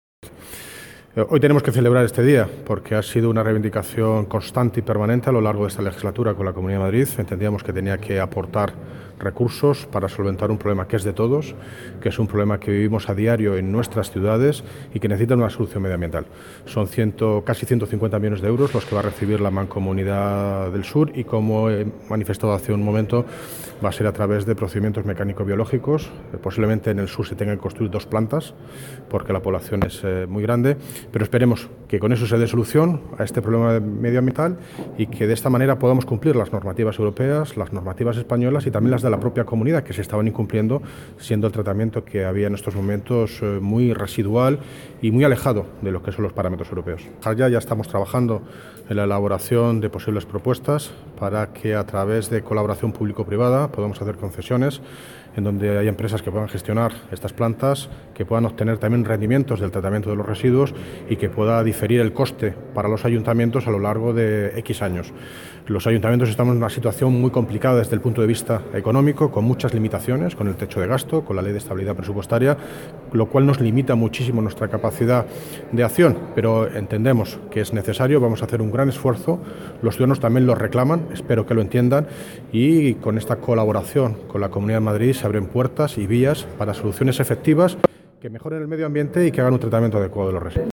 Audio - David Lucas (Alcalde de Móstoles) Sobre Programa Residuos Consejería Medio Ambiente